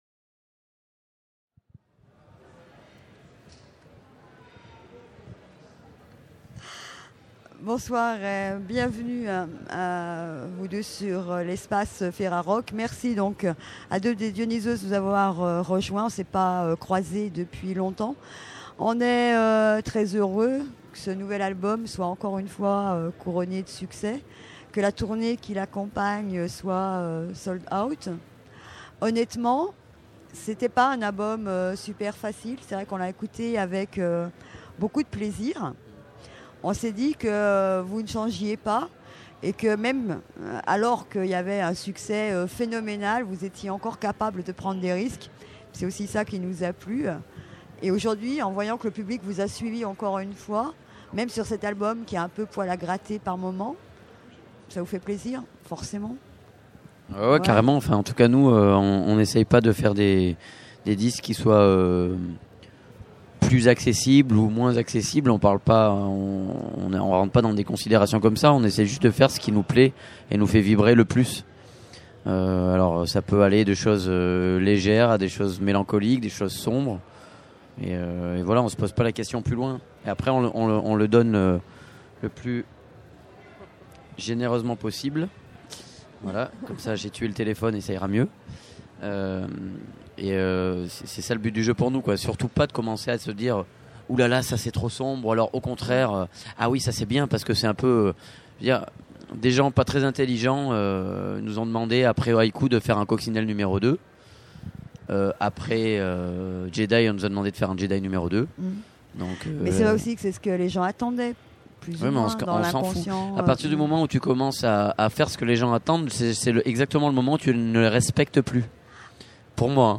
Dionysos Festival du Printemps de Bourges 2006 : 40 Interviews à écouter !